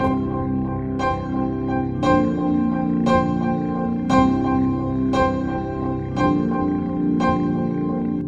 描述：2段50年代昏昏欲睡的钢琴旋律。
Tag: 58 bpm Soul Loops Piano Loops 1.40 MB wav Key : C